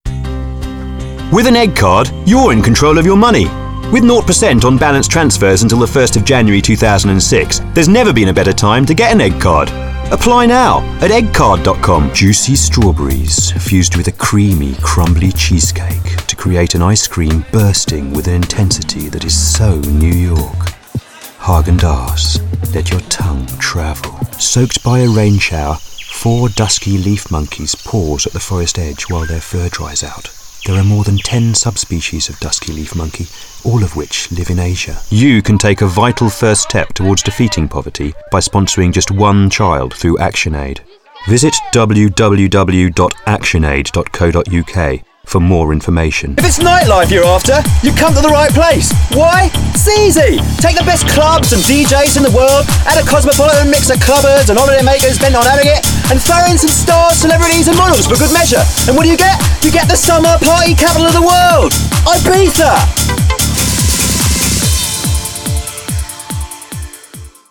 Rounded, warm, confident, smooth, informed, distinctive and slightly RP.
britisch
Sprechprobe: Werbung (Muttersprache):